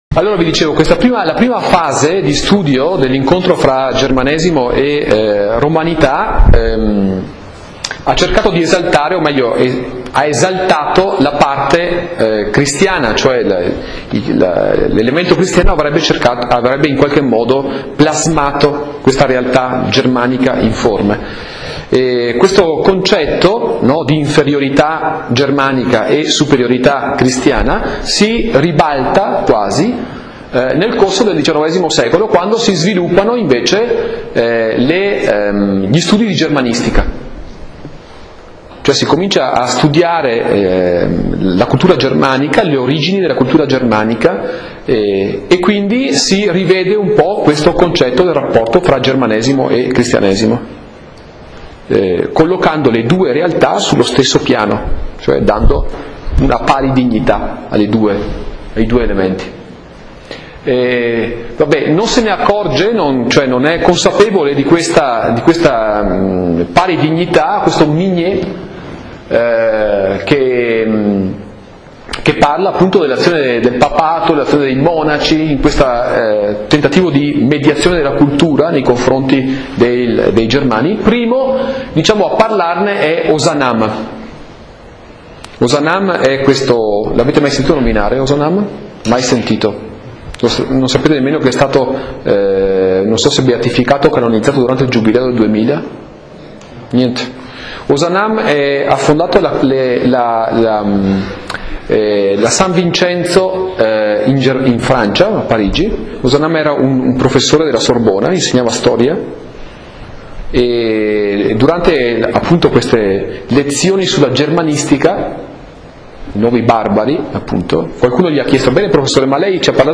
In Italian: 21� lezione - 10 marzo 2010